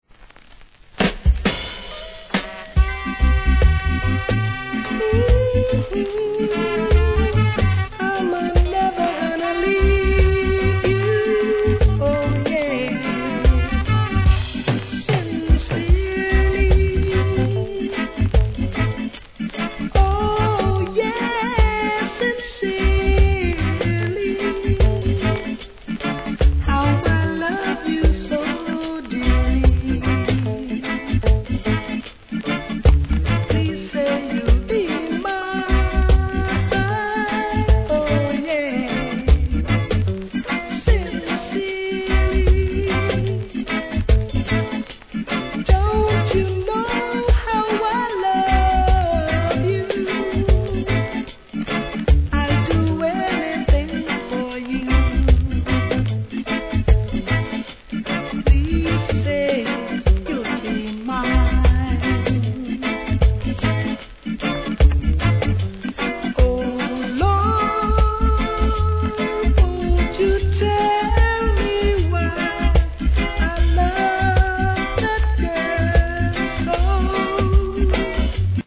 REGGAE
素晴らしいSWEETヴォーカルで聴かせます。